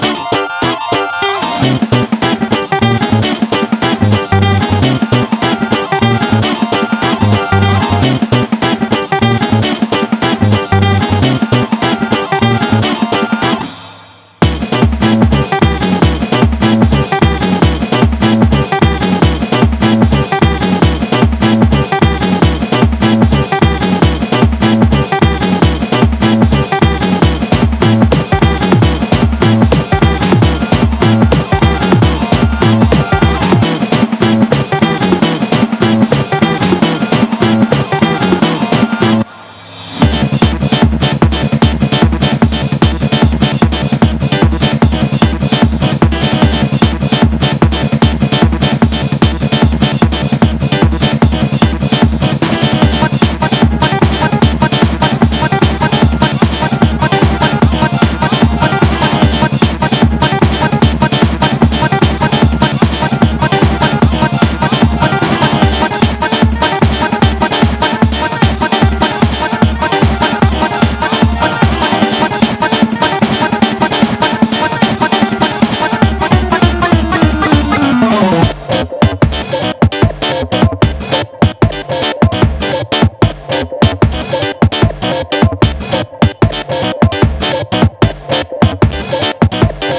ROCK / PUNK / 80'S～ / 80'S / NEW WAVE / 80' PUNK (JPN)
ネオアコ好きも必聴の泣きメロ・アイリッシュ・バンド！